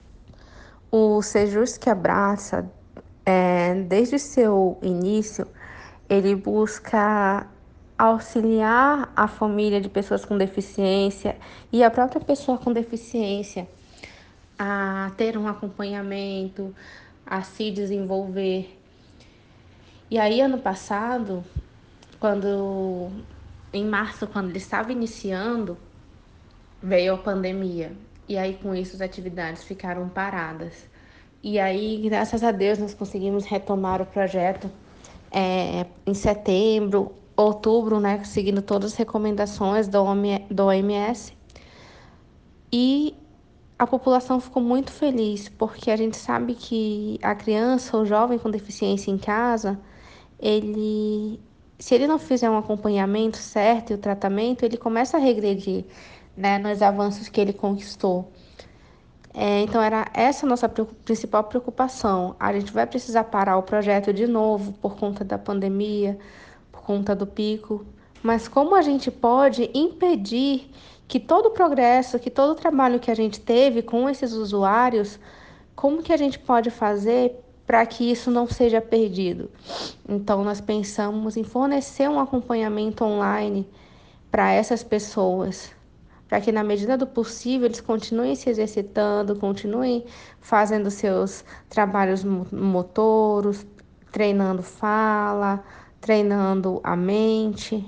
SONORA: Lêda Maia, secretária executiva das Pessoas com Deficiência